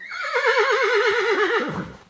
sounds_horse_neigh_02.ogg